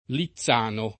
vai all'elenco alfabetico delle voci ingrandisci il carattere 100% rimpicciolisci il carattere stampa invia tramite posta elettronica codividi su Facebook Lizzano [ li zz# no o li ZZ# no secondo i casi] top. (E.-R.) — con -z- sonora Lizzano in Belvedere [ li zz# no im b H lved % re ], nell’Appennino; con -z- sorda Lizzano [ li ZZ# no ] presso Cesena